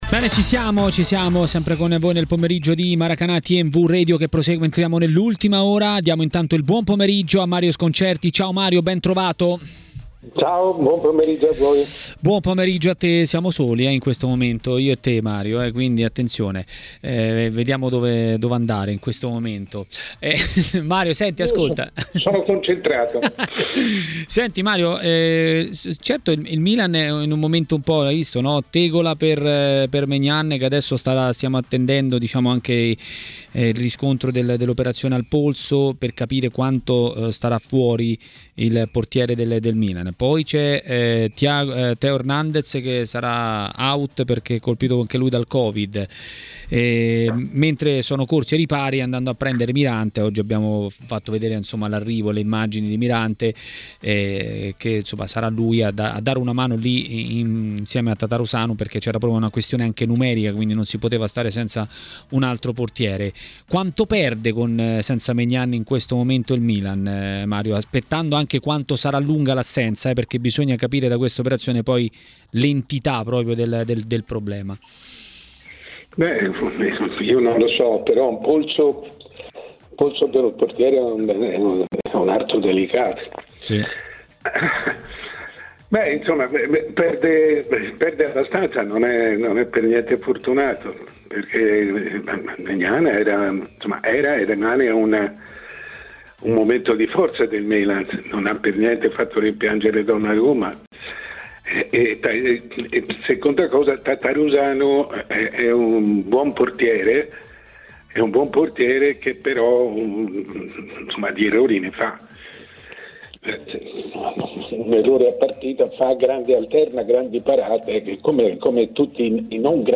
In diretta a TMW Radio, durante Maracanà, il direttore Mario Sconcerti, che ha parlato dei temi di giornata.